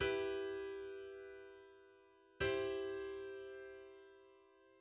In B minor, the resemblance between the subdominant (E-G-B) and the Neapolitan (E-G-C) is even stronger since only one note differs by a half-step.